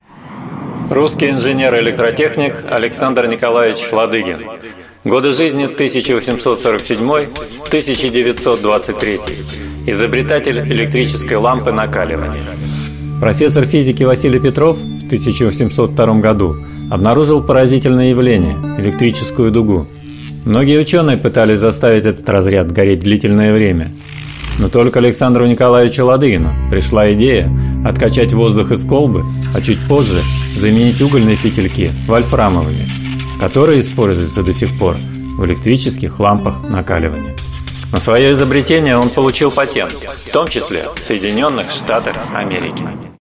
Аудиокнига Руси великие умы | Библиотека аудиокниг